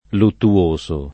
luttuoso